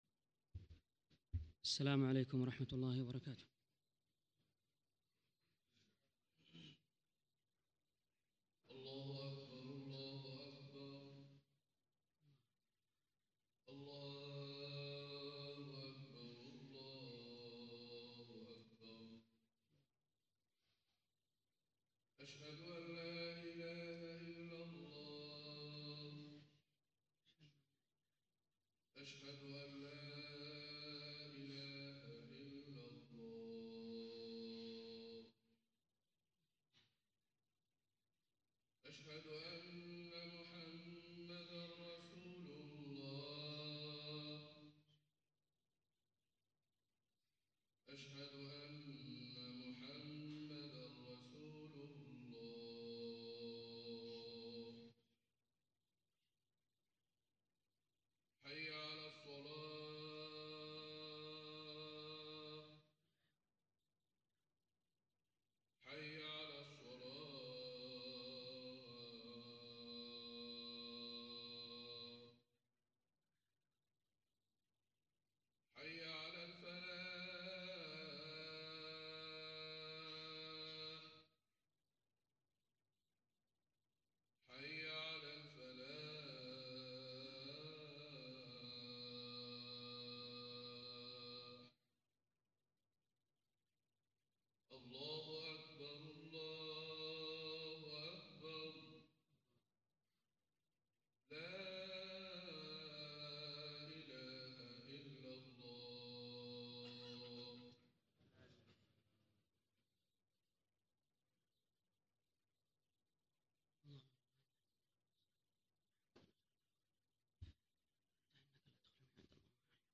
الخطبه
خطب الجمعة